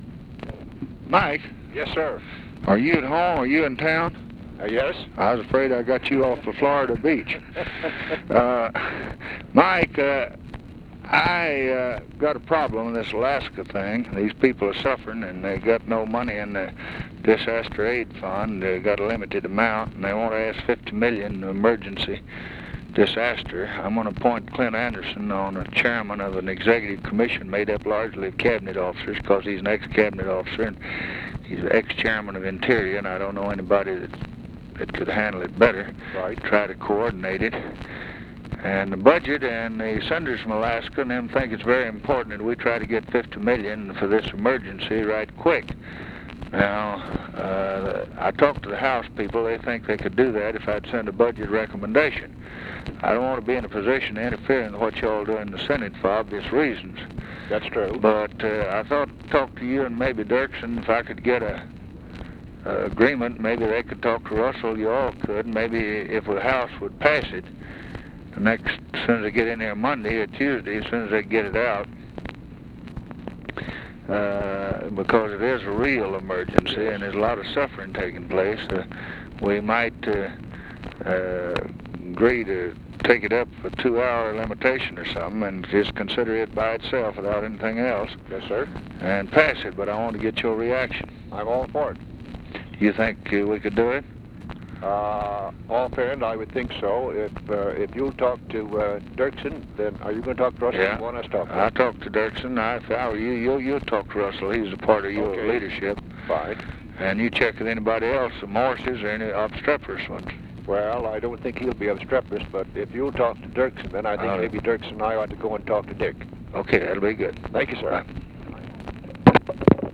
Conversation with MIKE MANSFIELD, April 1, 1964
Secret White House Tapes